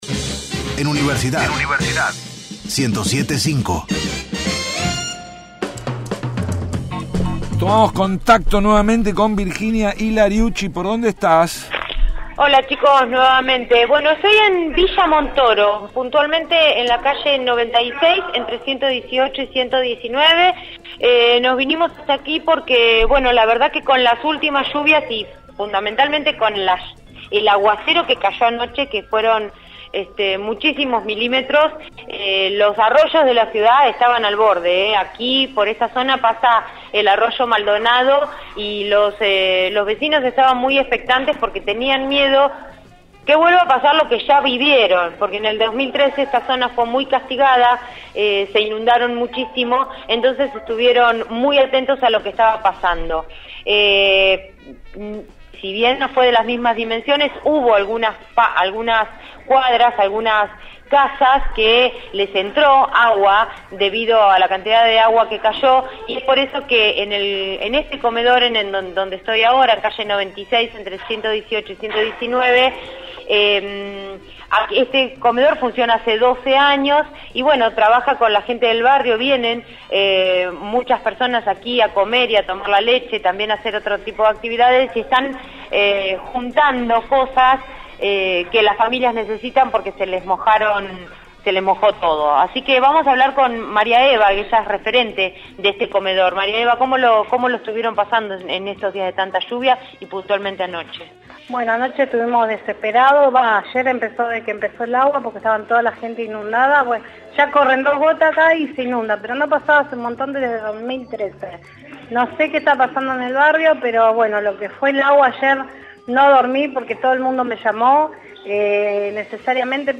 Móvil/ Inundación en Villa Montoro